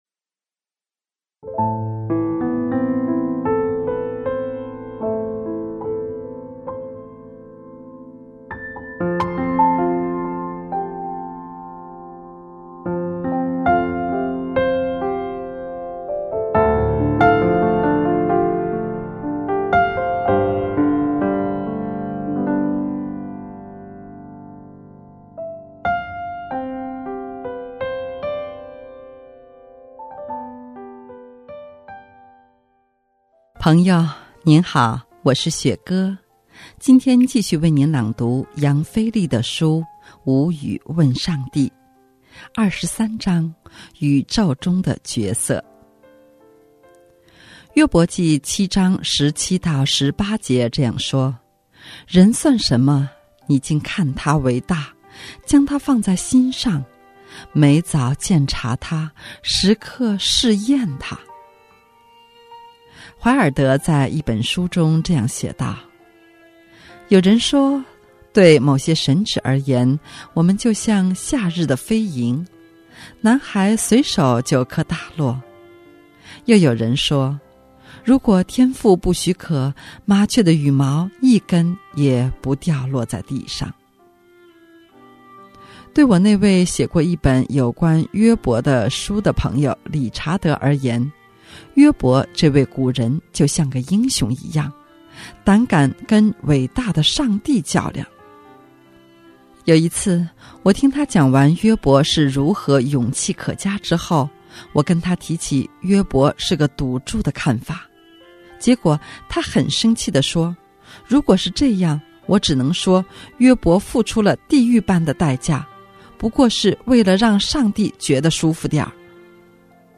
今天继续为你朗读杨腓力的书《无语问上帝》。